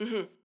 VO_ALL_Interjection_13.ogg